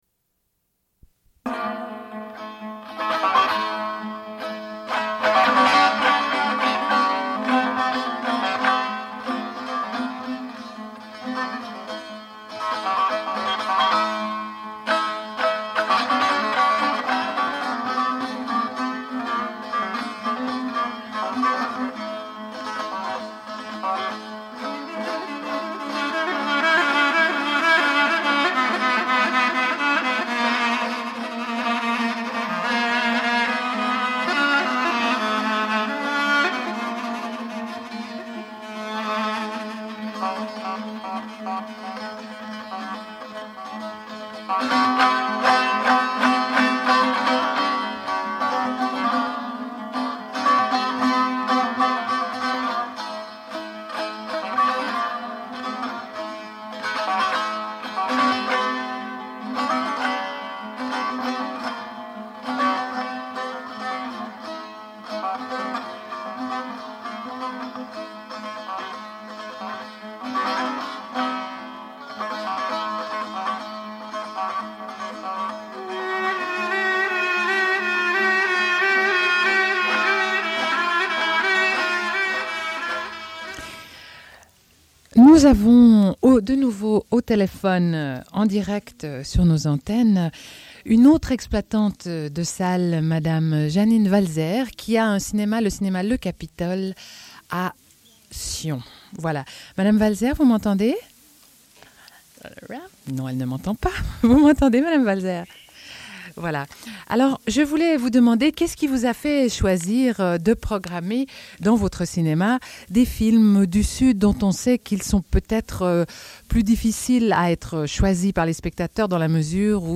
Une cassette audio, face A31:40
Radio Enregistrement sonore